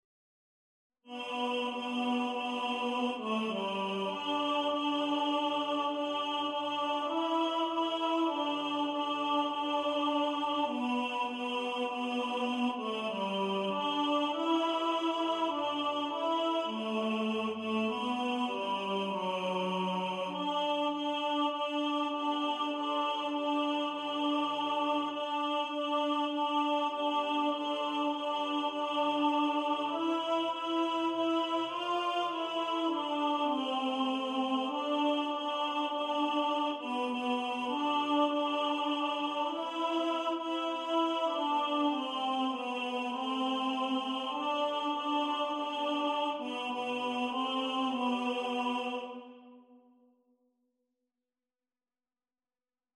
Hark! The Herald Angels Sing – Tenor | Ipswich Hospital Community Choir
Hark-The-Herald-Angels-Sing-Tenor.mp3